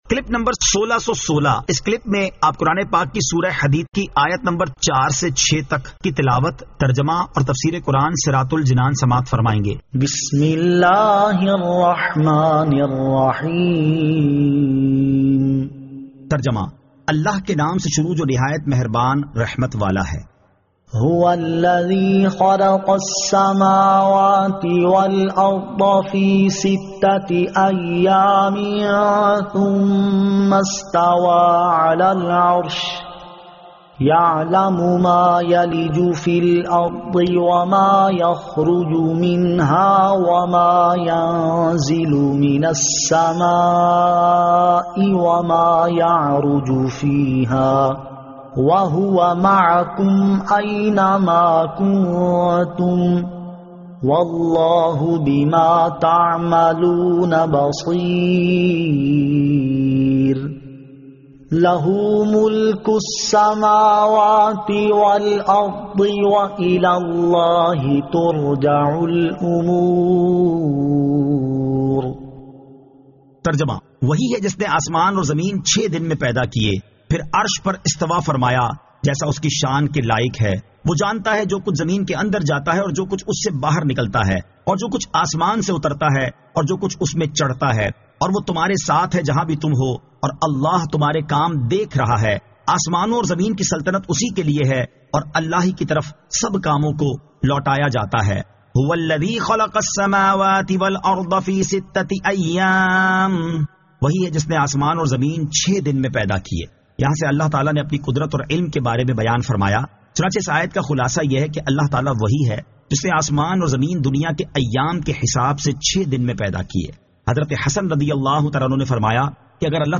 Surah Al-Hadid 04 To 06 Tilawat , Tarjama , Tafseer